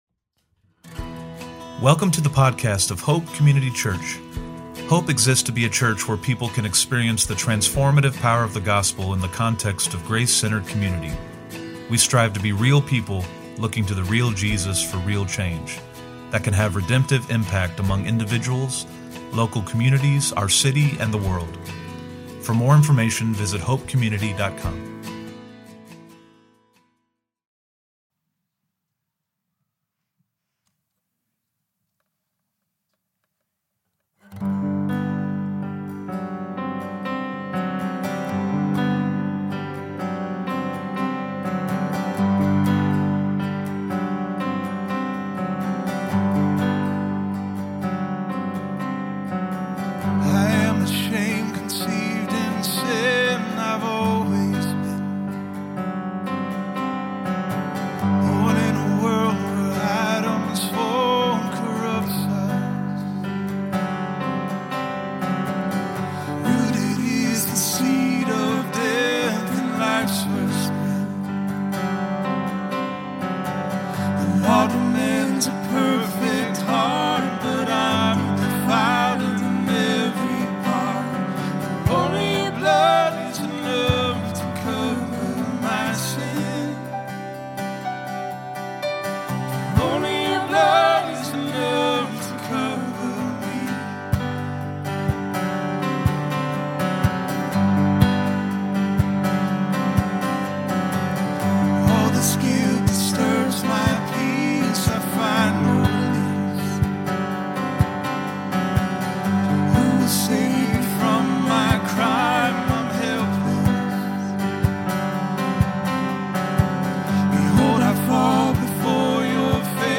From Location: "Cotswold"
CW-Sermon-11.2.25.mp3